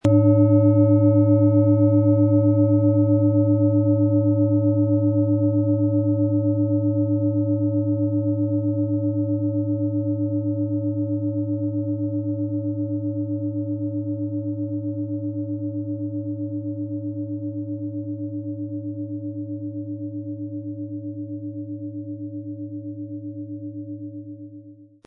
Nach uralter Tradition von Hand getriebene Klangschale.
Wie klingt diese tibetische Klangschale mit dem Planetenton Saturn?
Wohltuende Klänge bekommen Sie aus dieser Schale, wenn Sie sie mit dem kostenlosen Klöppel sanft anspielen.
MaterialBronze